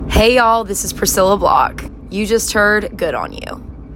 LINER Priscilla Block (Good On You) 5